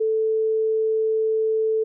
anhören (Kammerton ohne Obrtöne)